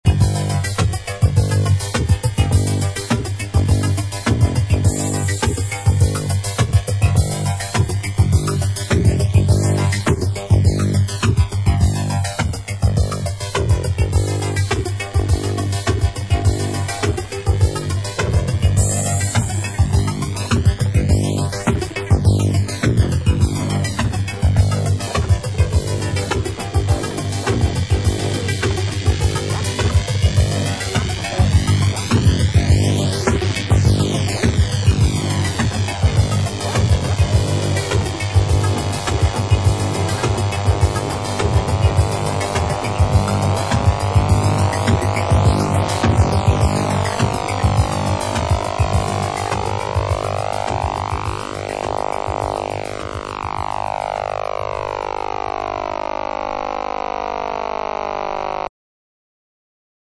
cosmic-electro-disco band